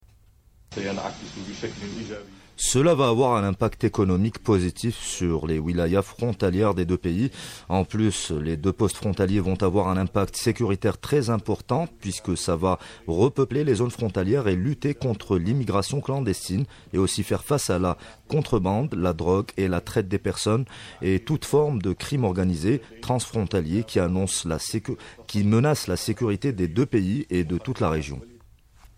Le ministre de l'Intérieur, Noureddine Bedoui s'exprime au micro des collègues de la radio locale de Tindouf Le ministre de l'Intérieur mauritanien, Ahmadou Ould-Abdellah s’exprime à la radio locale de Tindouf